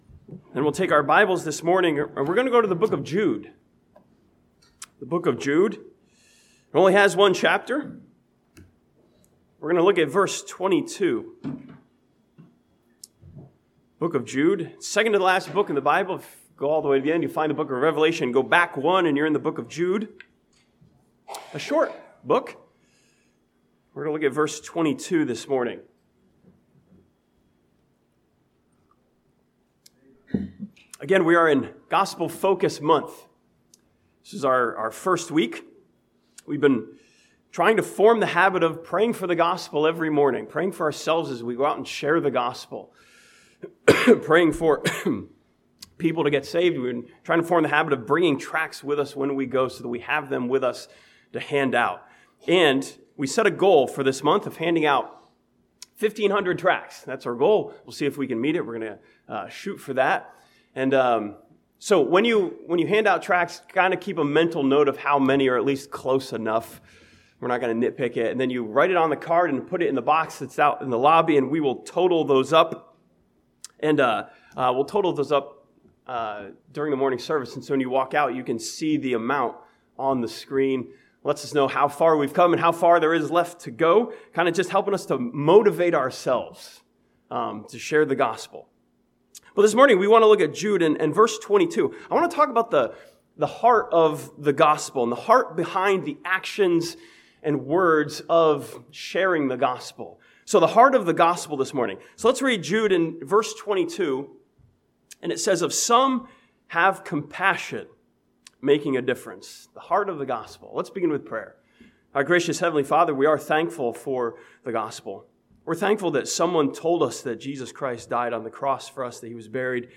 This sermon from the 22nd verse of Jude studies the crucial aspect of compassion as the heart of the Gospel.